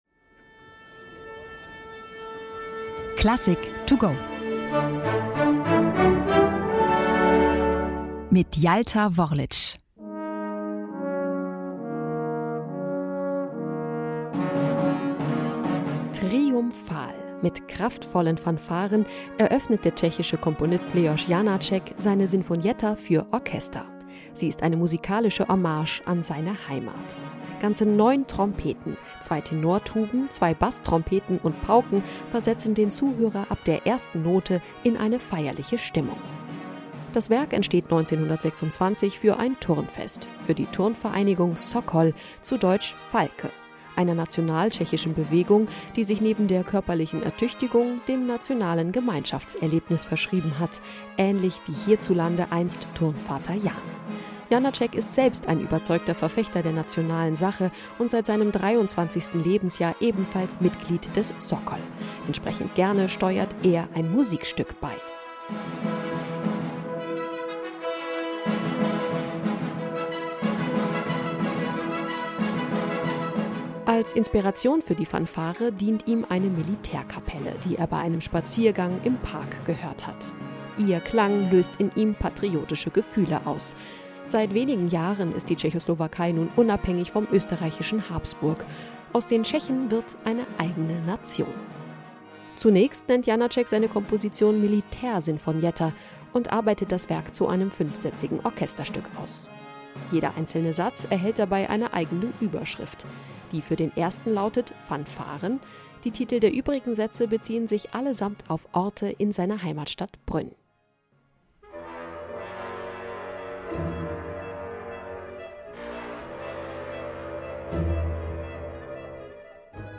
Werkeinführung für unterwegs